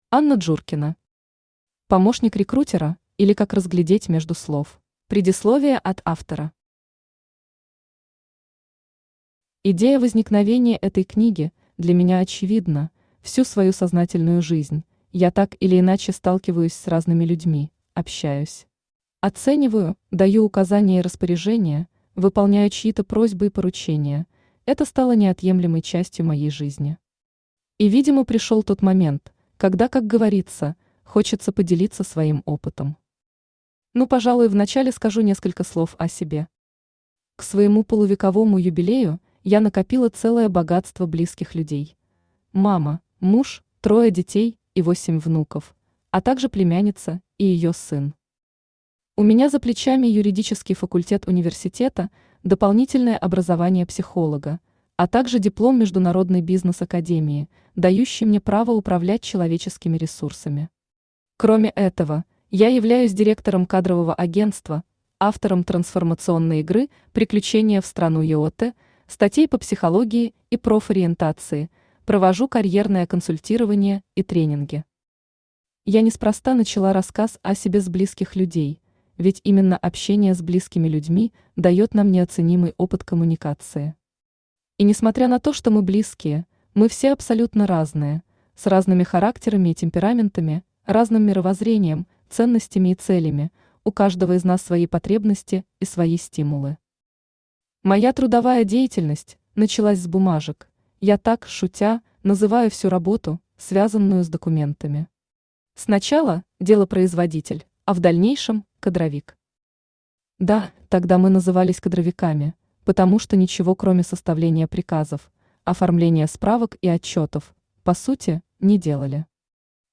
Автор Анна Анатольевна Джуркина Читает аудиокнигу Авточтец ЛитРес.